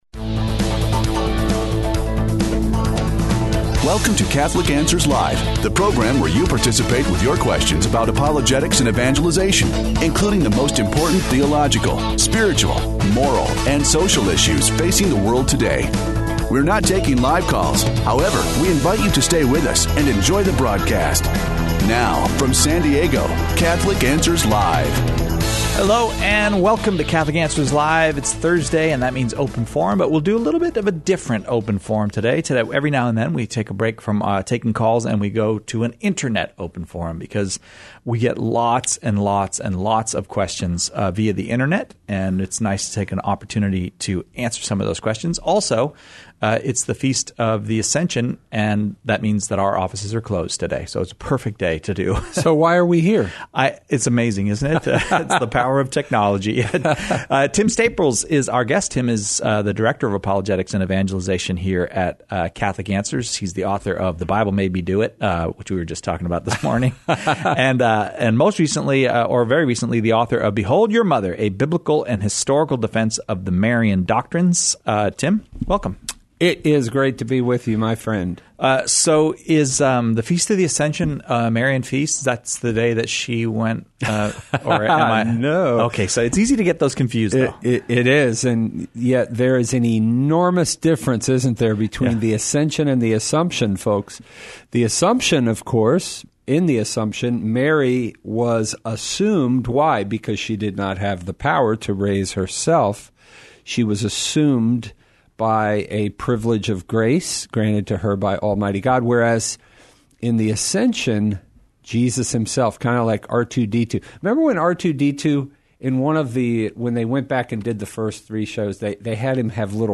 Open Forum (Pre-recorded)